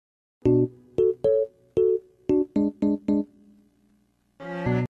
Play, download and share POPpppp original sound button!!!!
pop_7ulPyTw.mp3